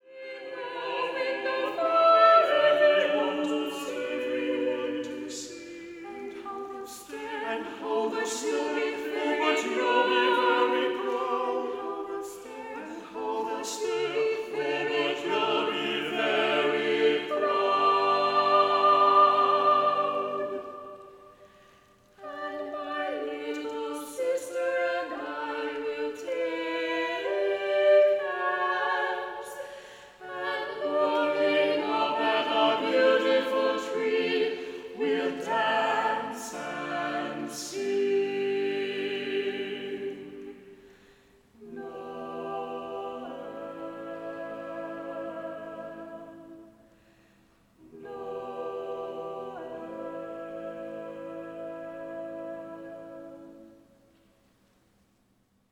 MIXED CHORUS
A Cappella
SATB